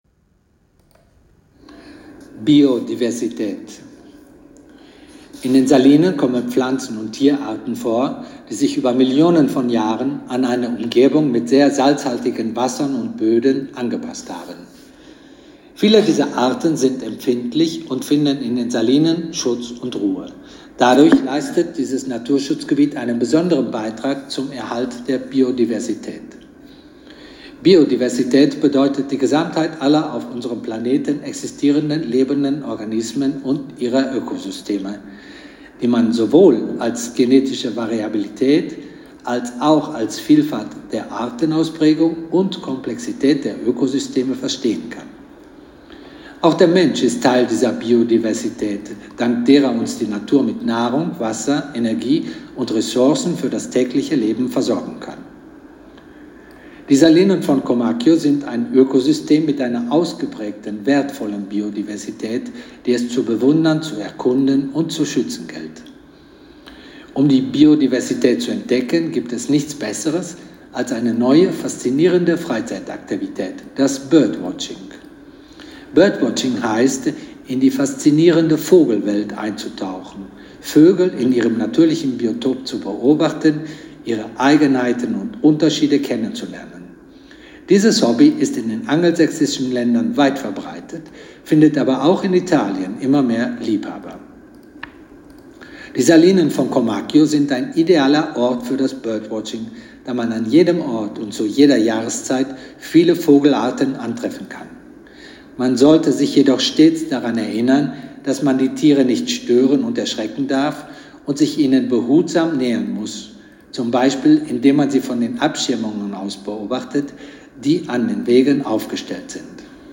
Audioguides auf Deutsch - Salina di Comacchio